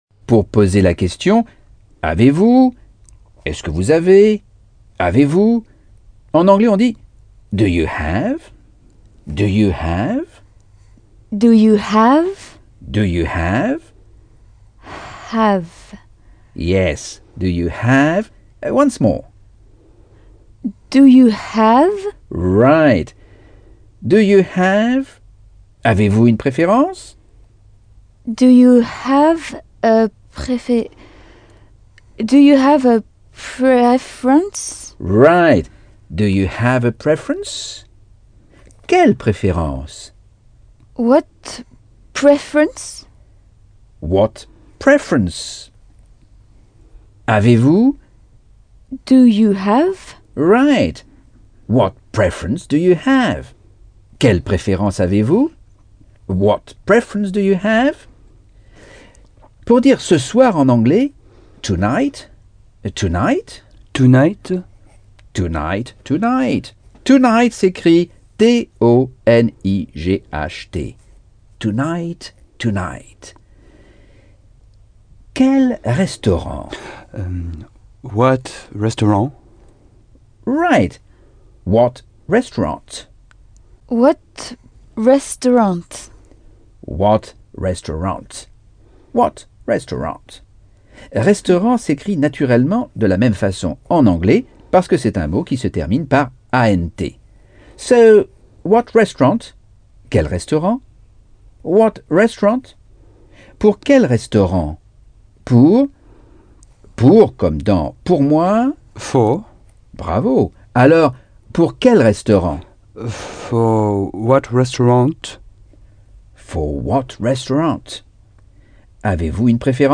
Leçons 8 - Anglais audio par Michel Thomas